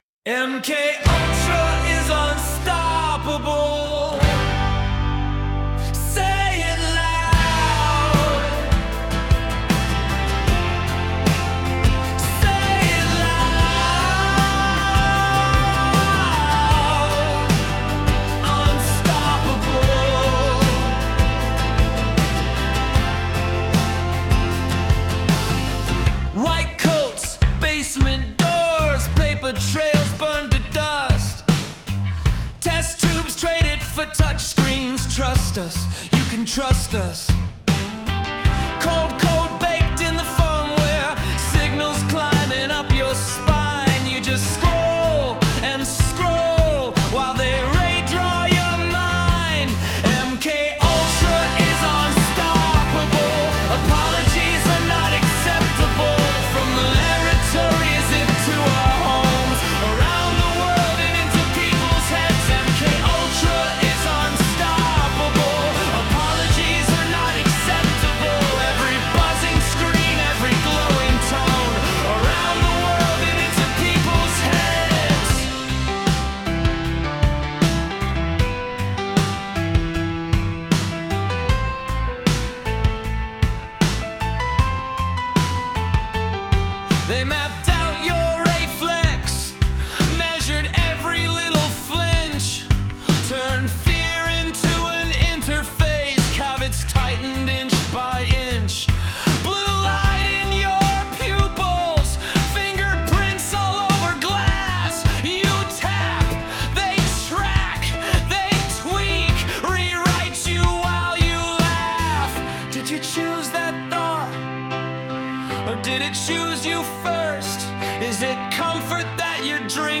ELECTRIC, Electric SPARKS, Hard Disco